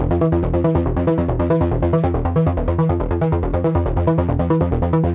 Index of /musicradar/dystopian-drone-samples/Droney Arps/140bpm
DD_DroneyArp4_140-C.wav